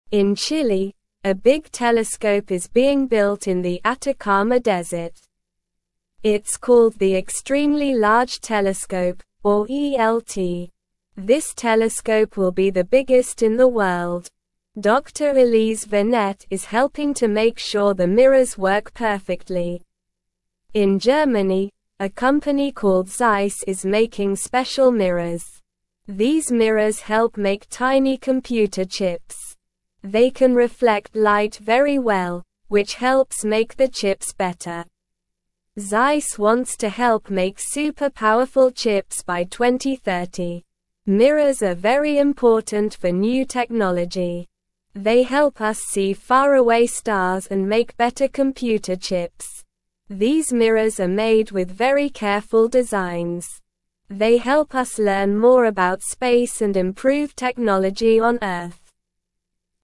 Slow
English-Newsroom-Lower-Intermediate-SLOW-Reading-Building-a-Big-Telescope-and-Making-Smooth-Mirrors.mp3